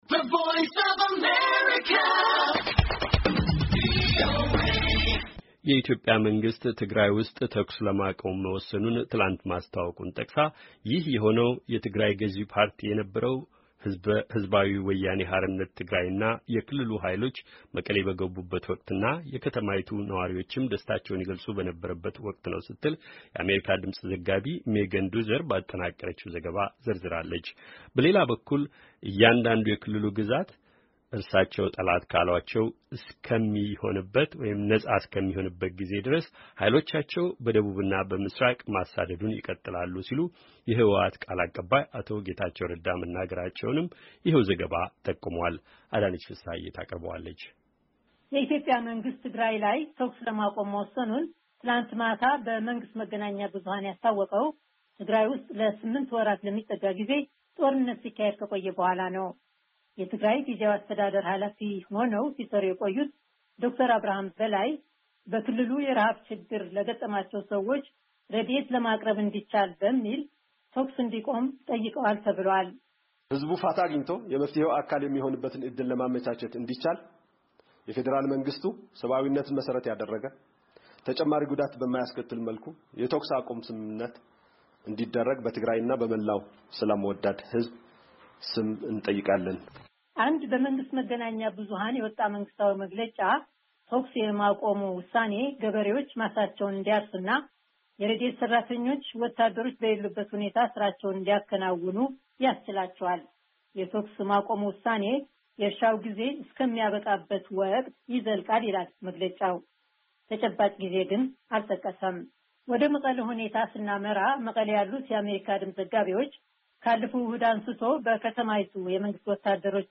የትግራይ ሁኔታ ዛሬ - አጠቃላይ ዘገባ